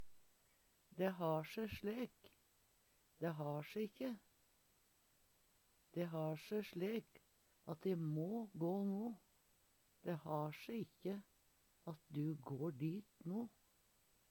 dæ har se slek, dæ har se ikkje - Numedalsmål (en-US)